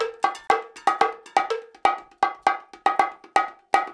120 BONGOS7.wav